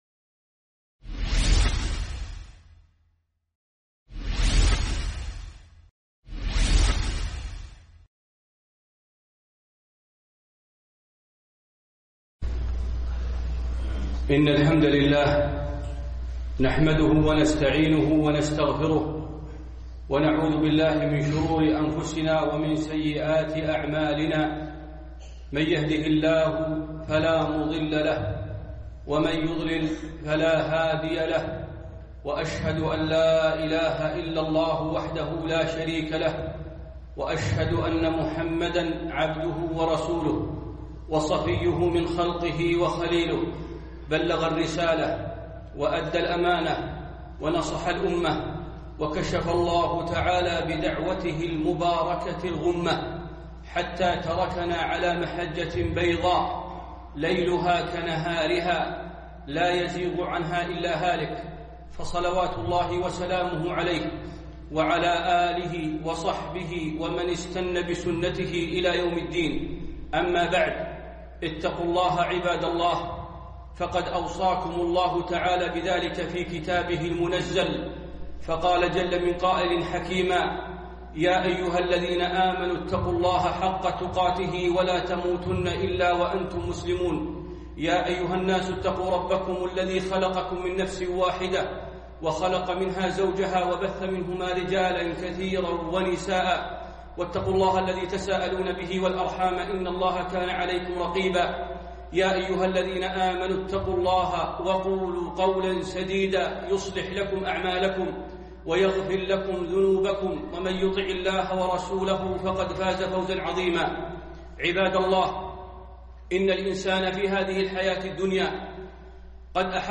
النفس الأمارة بالسوء - خطبة